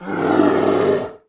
Bear Roar
战熊吼叫